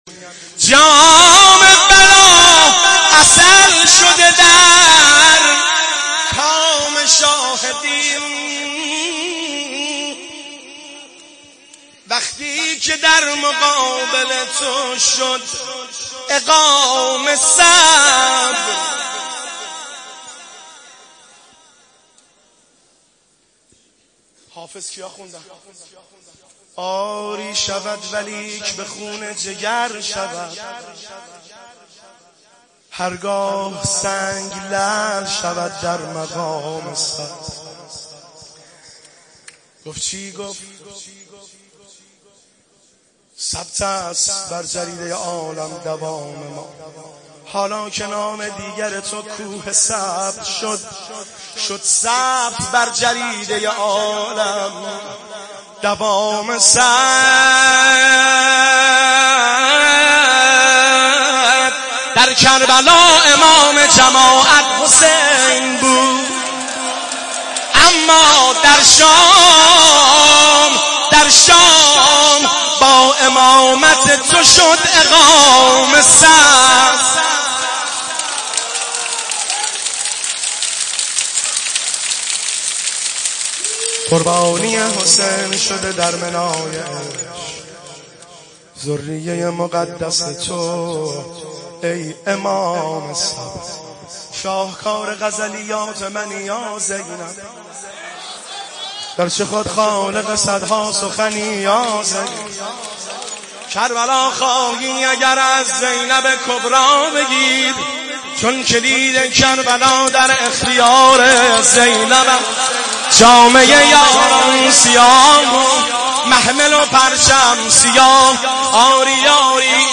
روضه حضرت زینب